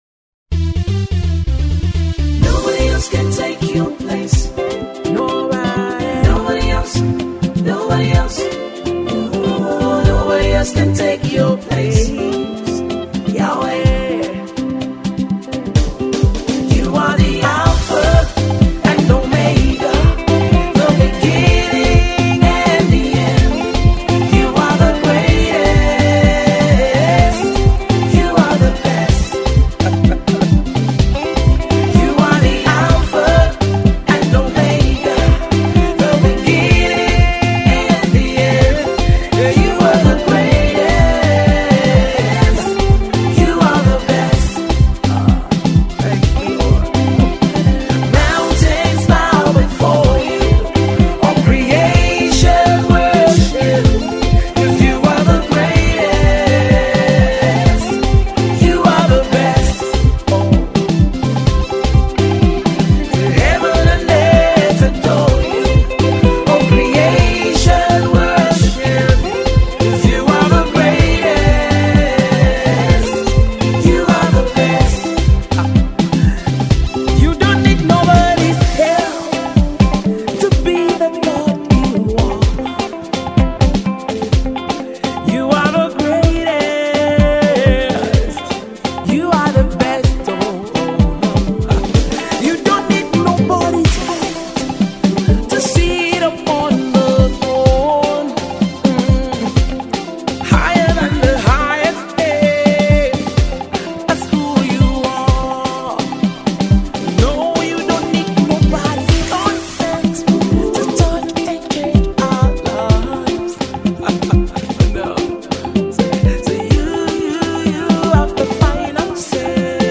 gospel music
uptempo song